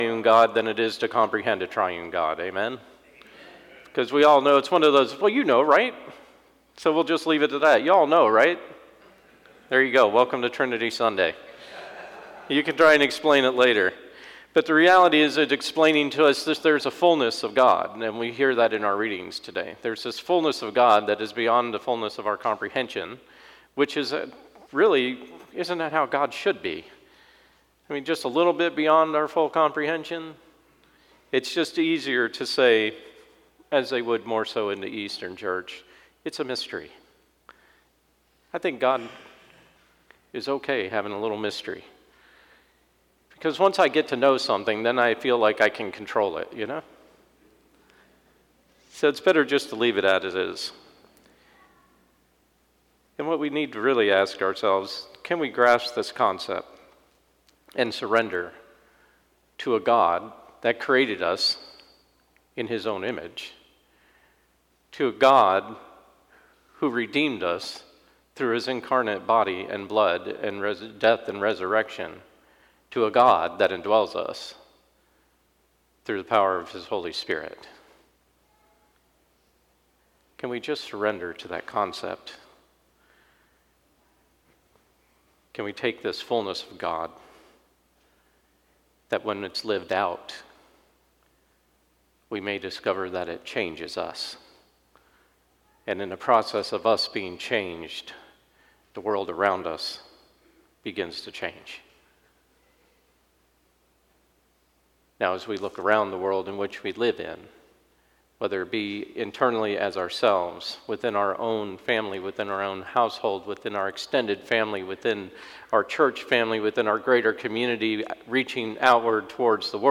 Sermon 5/26/24 First Sunday after Pentecost: Trinity Sunday